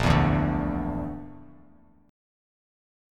BbMb5 chord